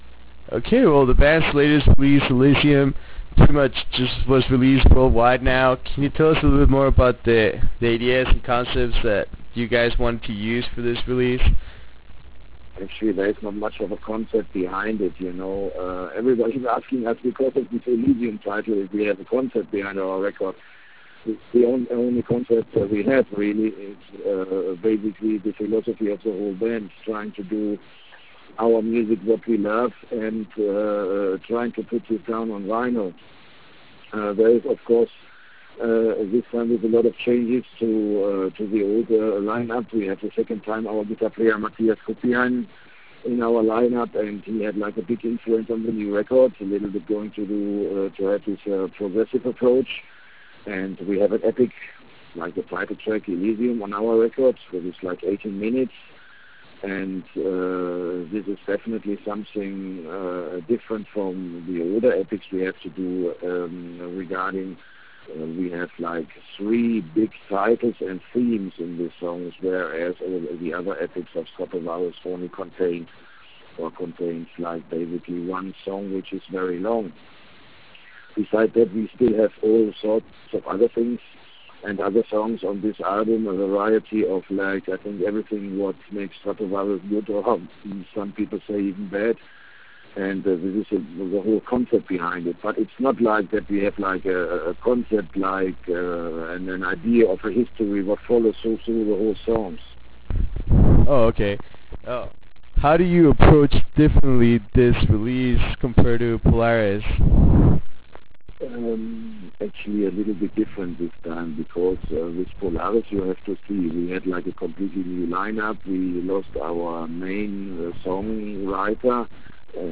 Interview with Stratovarius - Jorg Michael
In the eve of the worldwide release of “Elysium”, we managed to catch up with Stratovarius drummer Jörg Michael during their European tour in Rome. In this 20 minute interview we discuss the band’s integration in creating their latest release. We also talk about their future touring plans that might include North America and Jörg’s opinion on the music industry and younger bands that try to be the fastest in the world.
Interview with Jorg Michael - Stratovarius.wav